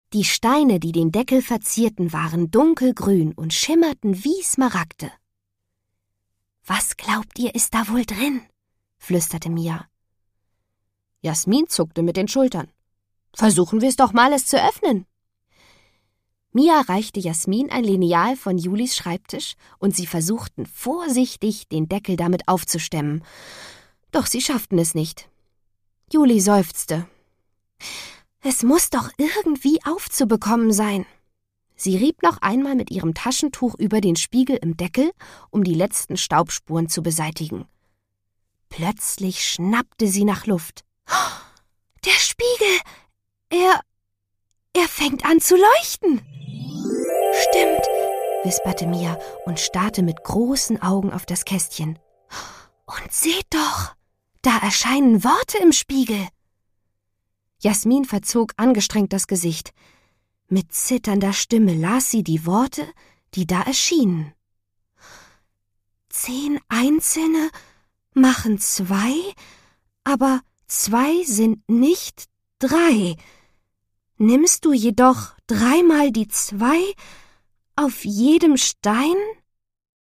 Produkttyp: Hörbuch-Download
Fassung: Ungekürzte Lesung